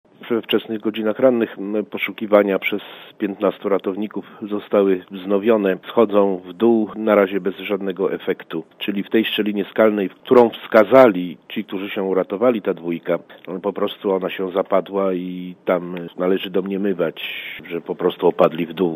Mówi Tomasz Klimański, konsul generalny w Moskwie
O wyprawie ratunkowej - mówi Radiu ZET Tomasz Klimański polski konsul generalny w Moskwie: